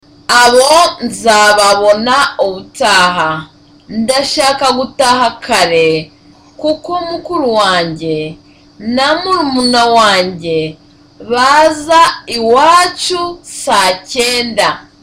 (Politely.)